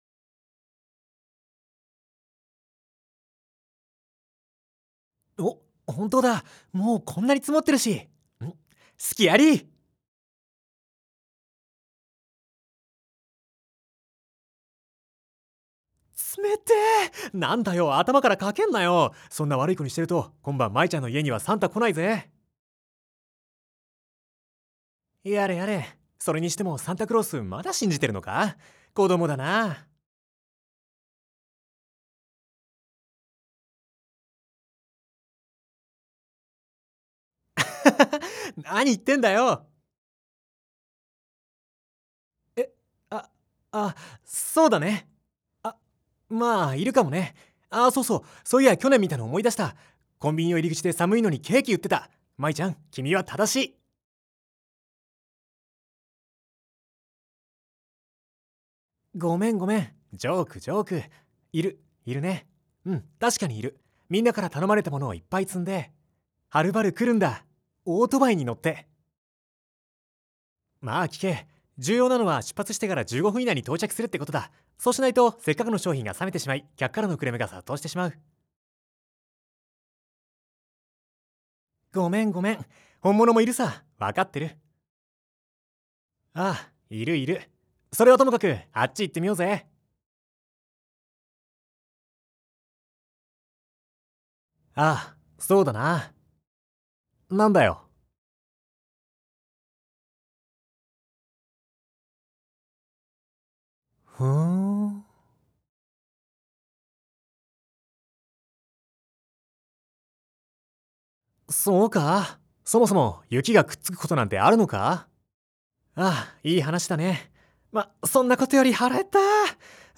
4. 声優によるオリジナル原稿の読み上げ（台詞を連結してストーリーにしたもの）
男性：VM01
女性：VF01
11.31 ［モーラ/秒］ 試聴／ダウンロード 試聴／ダウンロード
絨毯敷，カーテン有．